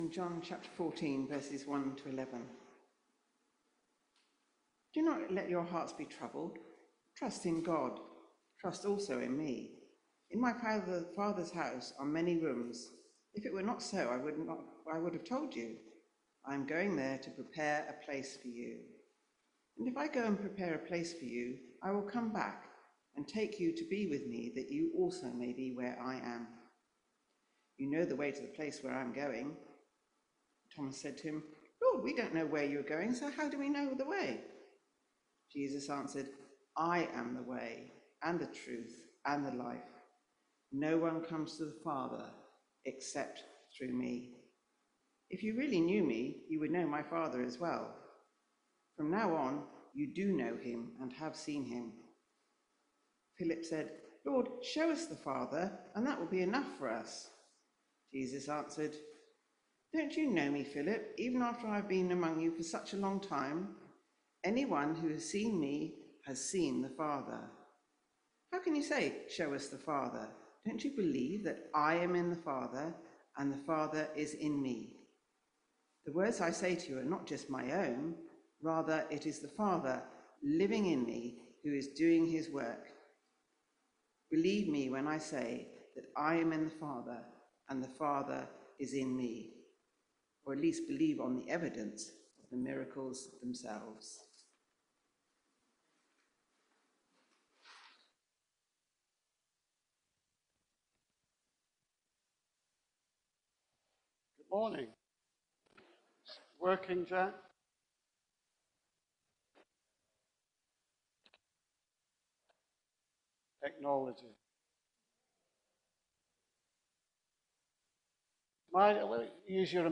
Sermon-20-5-25-audio.mp3